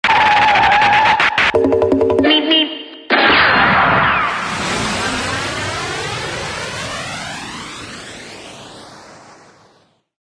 Meep 2 sound effects free download